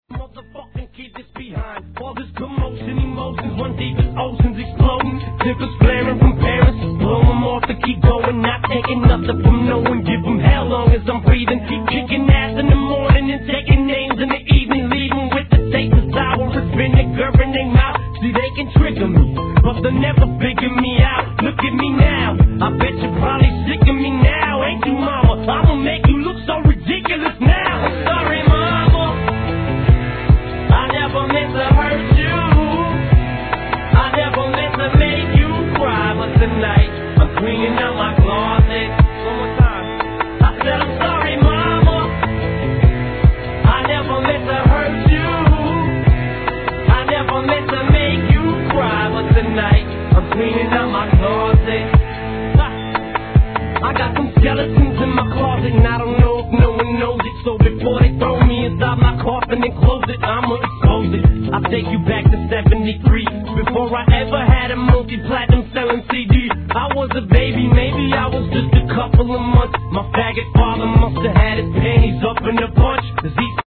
HIP HOP/R&B
哀愁 メロを前面に出したらしい一曲!